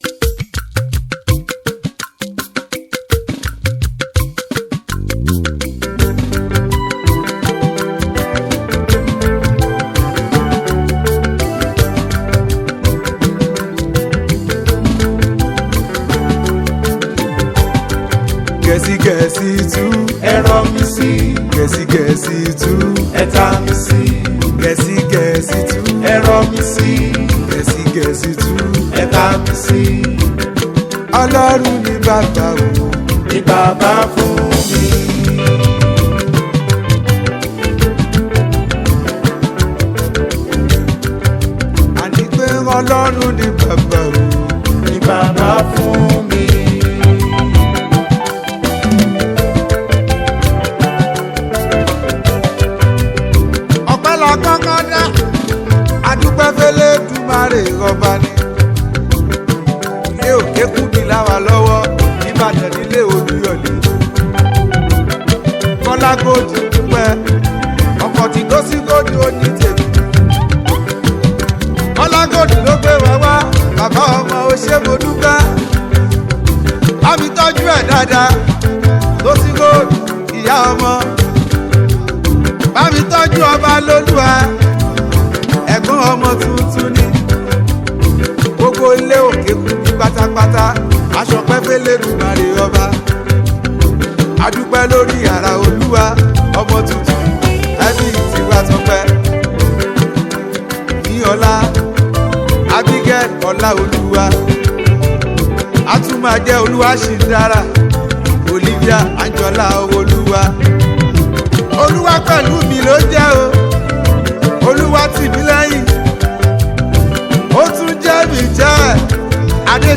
Fuji Music